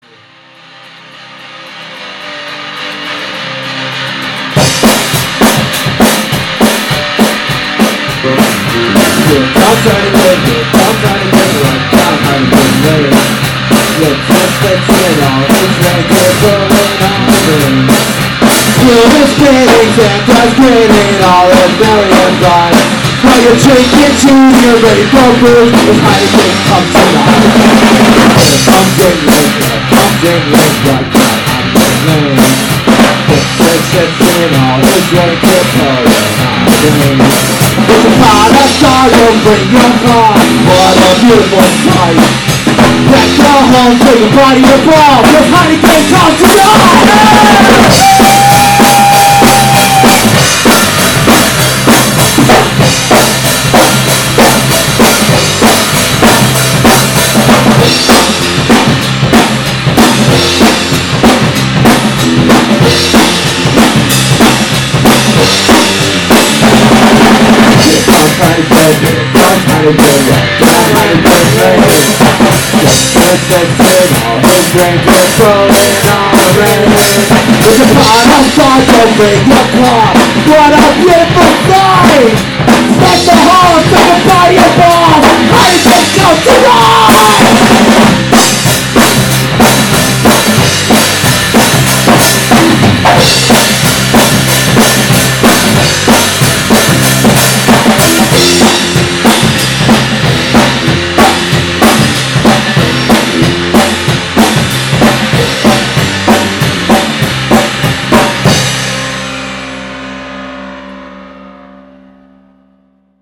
Christmas Songs!